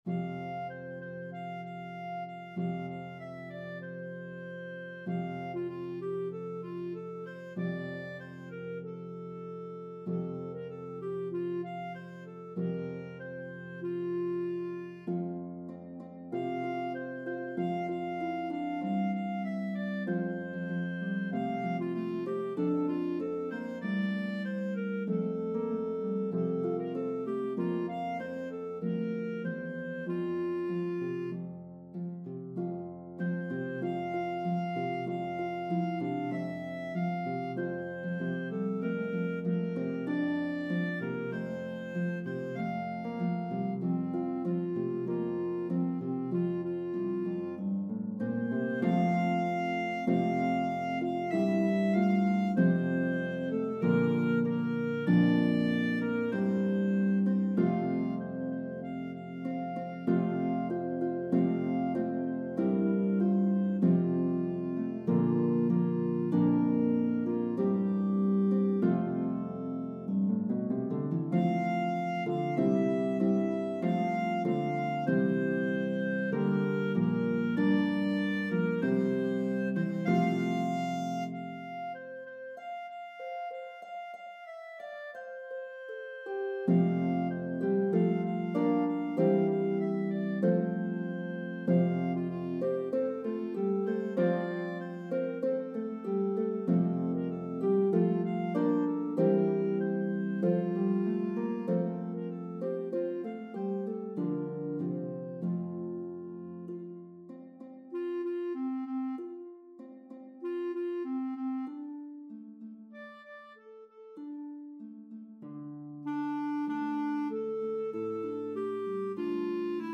a medley of traditional English & Irish Carols